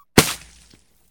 slime.ogg